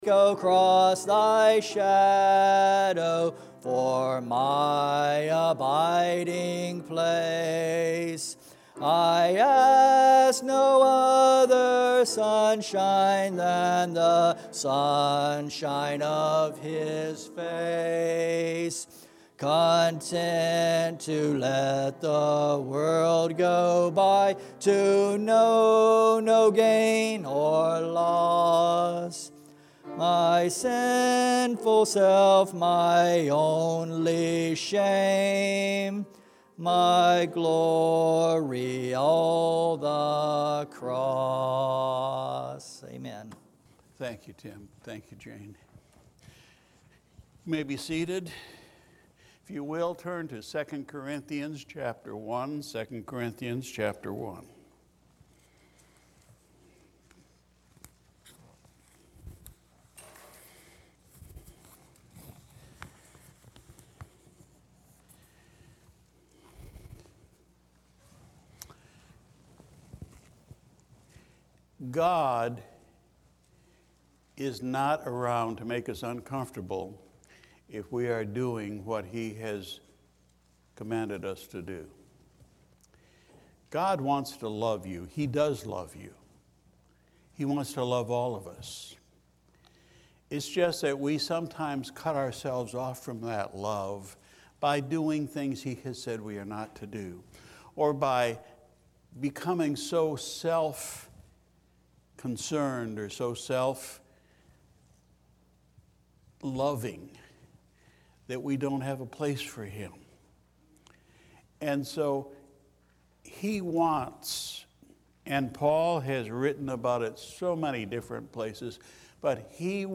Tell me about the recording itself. October 4, 2020 Sunday Morning Service Title: “The God of all Comfort” (there is a delay before the service begins)